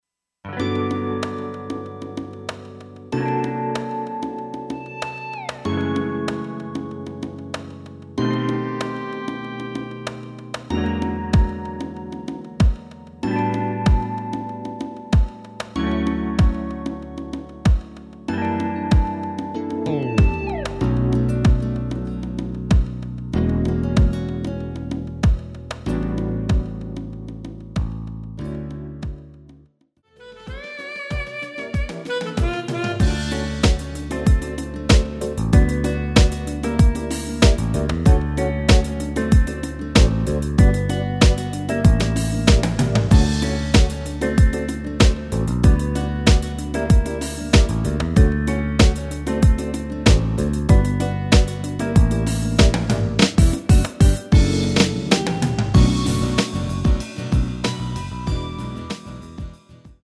대부분의 남성분이 부르실수 있는 키로 제작 하였습니다.
앞부분30초, 뒷부분30초씩 편집해서 올려 드리고 있습니다.